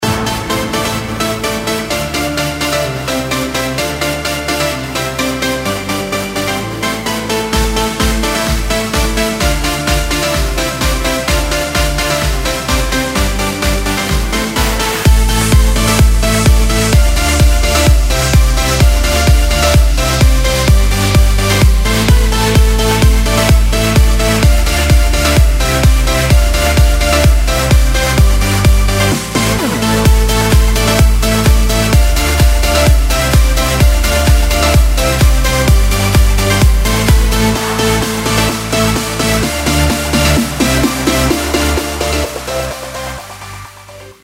• Качество: 128, Stereo
electro